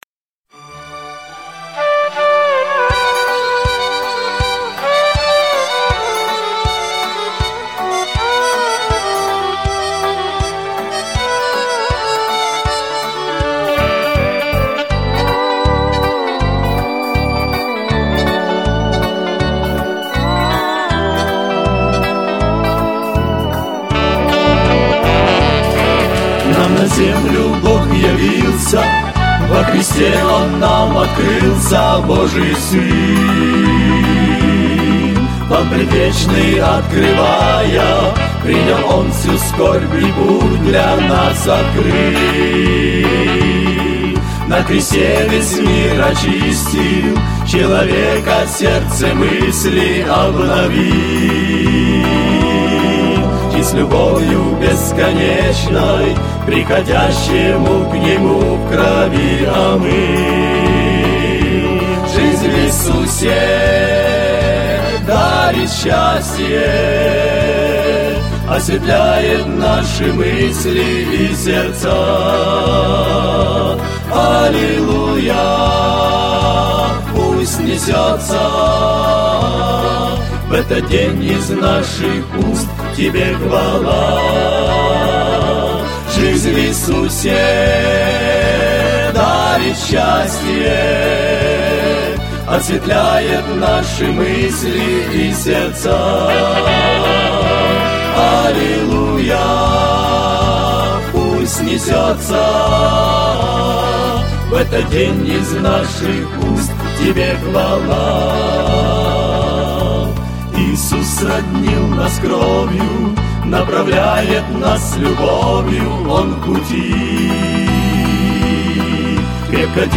песня
343 просмотра 636 прослушиваний 32 скачивания BPM: 79